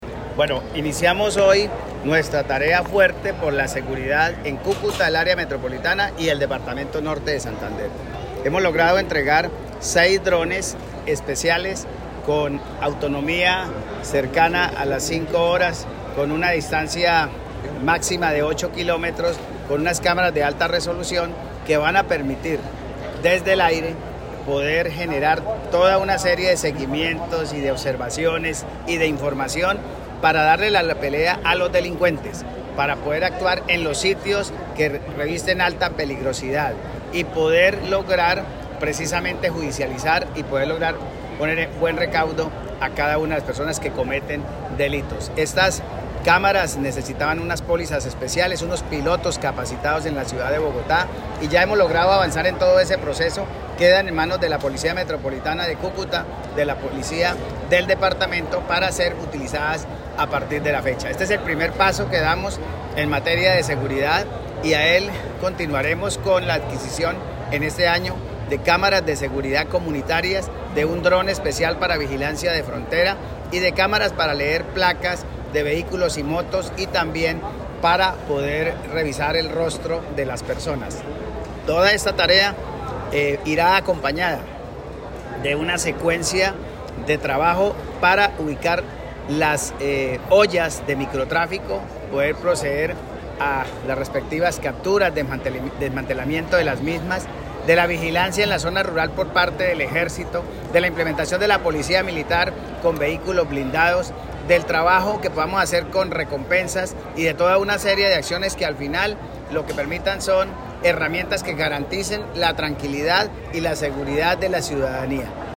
Audio-del-gobernador-William-Villamizar.mp3